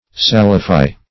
Search Result for " salify" : The Collaborative International Dictionary of English v.0.48: Salify \Sal"i*fy\, v. t. [imp.